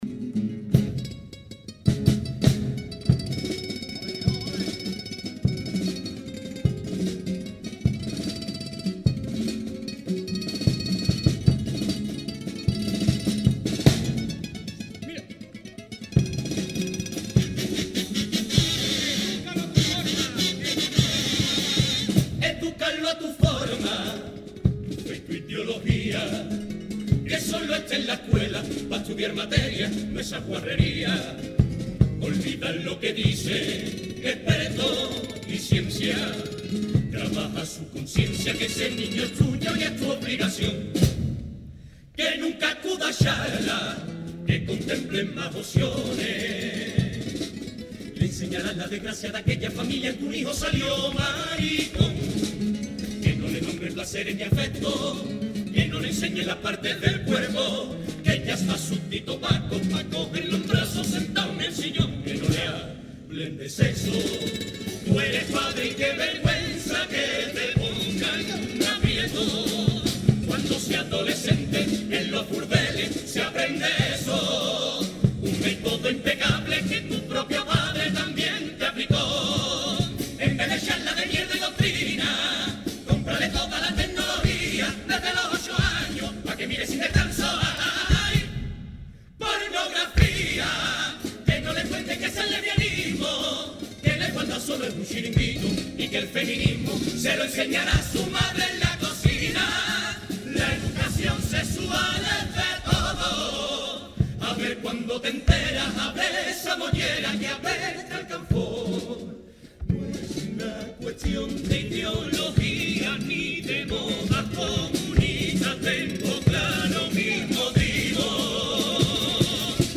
El segundo premio del Fermín Salvochea 2020 ha sido para el coro de adultos ‘La Colonial’ y la letra premiada